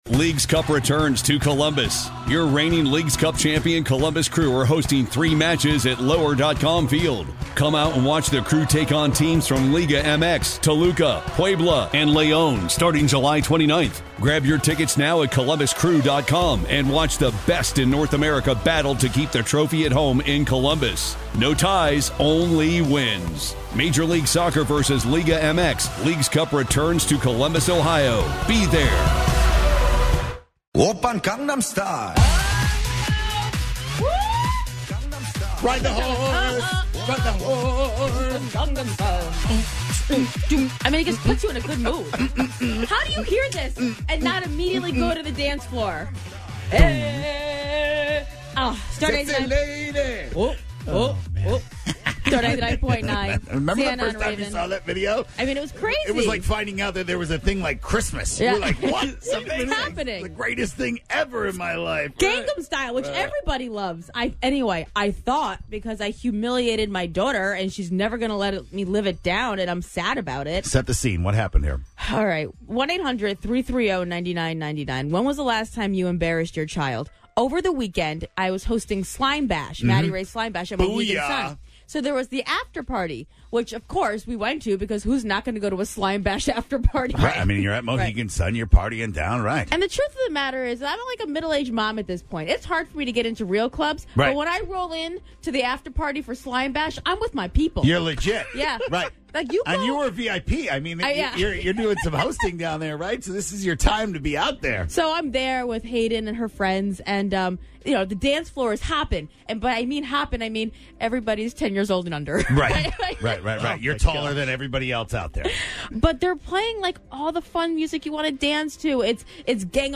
the listeners called in with the creative ways they embarrass their own kids!